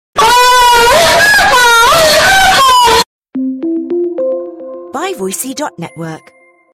Super Loud Aahhh Efecto de Sonido Descargar
Super Loud Aahhh Botón de Sonido